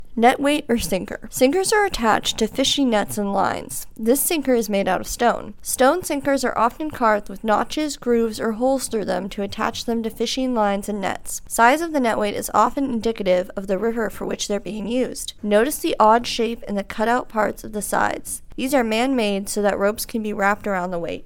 The audio guide to the kits is provided below.